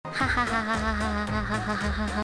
Play Fenneko Laugh - SoundBoardGuy
PLAY fenneko troll laugh
fenneko-laugh.mp3